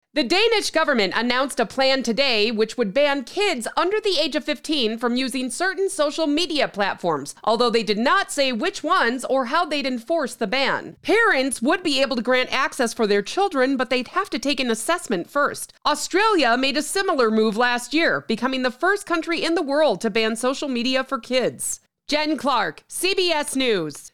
News Demo
Middle Aged